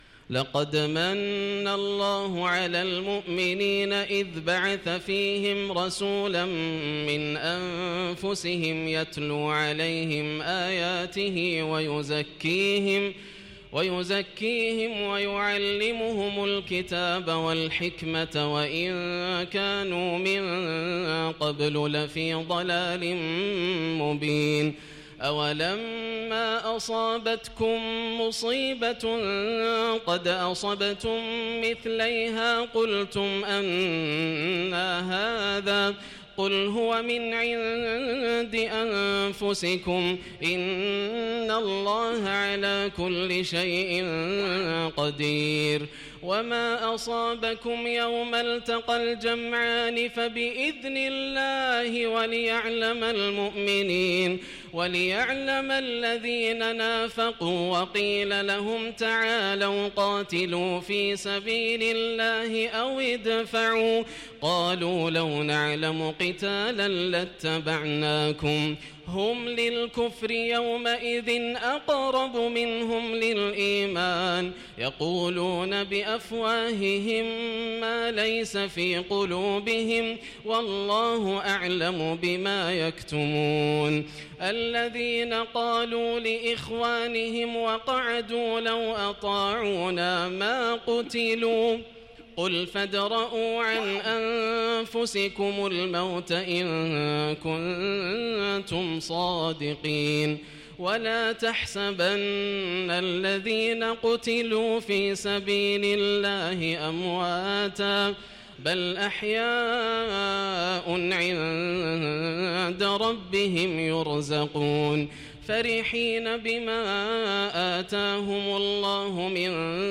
ولا تحسبن الذين قتلوا في سبيل الله أمواتا - تلاوة جميلة جداً بالبيات > الروائع > رمضان 1437هـ > التراويح - تلاوات ياسر الدوسري